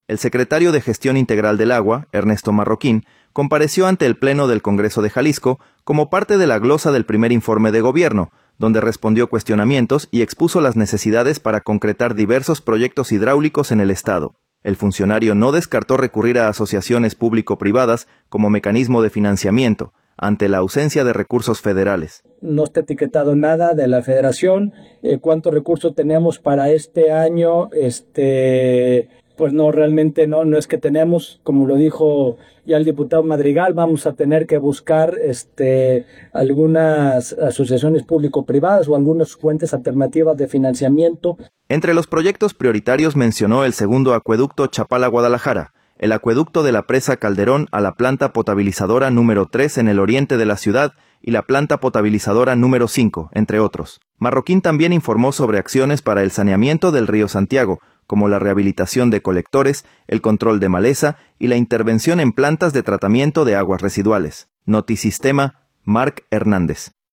El secretario de Gestión Integral del Agua, Ernesto Marroquín, compareció ante el pleno del Congreso de Jalisco como parte de la glosa del primer informe de Gobierno, donde respondió cuestionamientos y expuso las necesidades para concretar diversos proyectos hidráulicos en el estado. El funcionario no descartó recurrir a Asociaciones Público–Privadas (APP) como mecanismo de financiamiento, ante la ausencia de recursos federales.